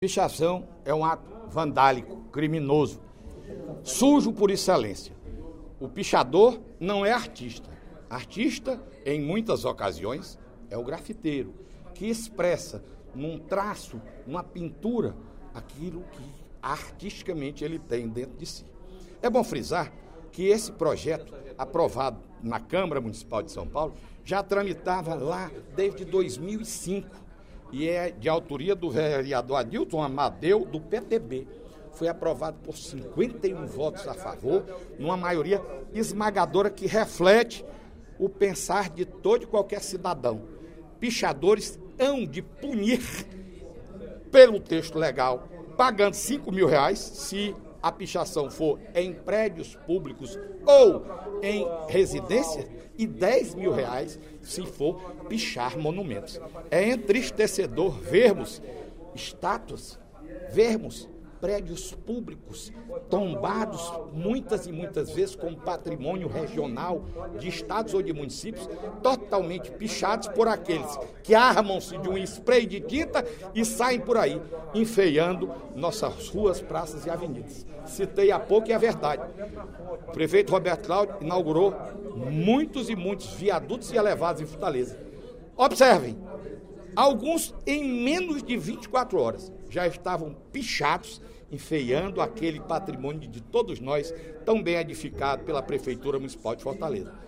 O deputado Fernando Hugo (PP) defendeu, nesta quinta-feira (23/02), durante o primeiro expediente da sessão plenária da Assembleia Legislativa, medidas firmes contra pichadores. Para ele, os pichadores não são considerados artistas, como, em muitas ocasiões, é o grafiteiro, que expressa numa pintura sentimento de paisagem e visão de mundo.